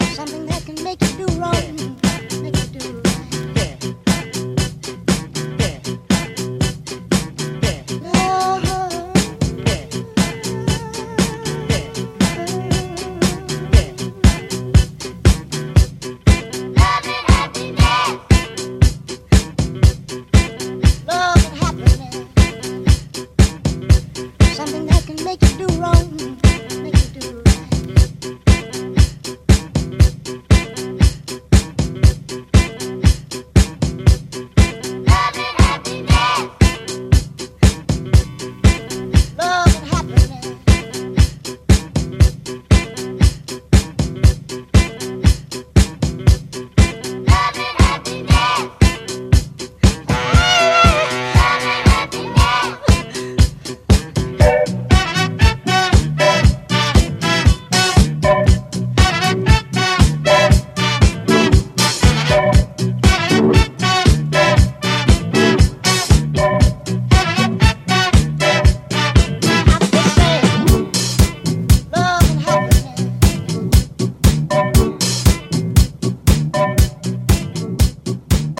Techno (minimal)